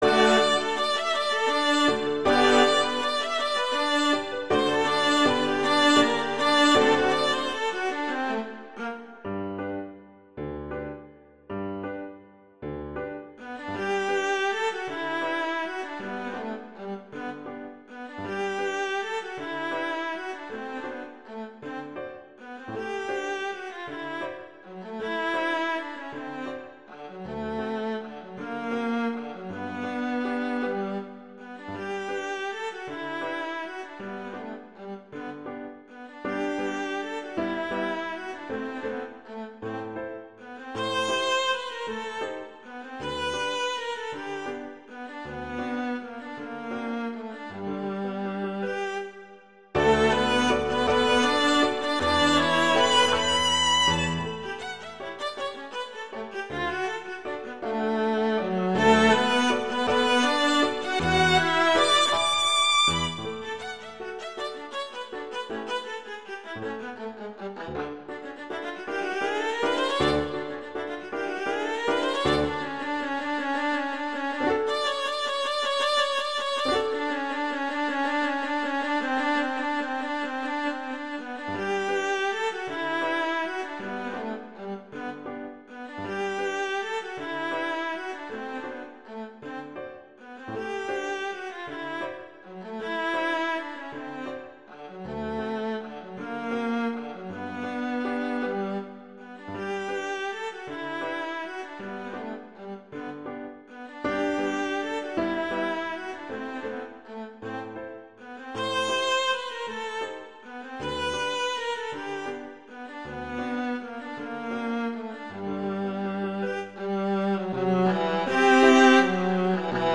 classical, wedding, festival, love
G major
♩=160 BPM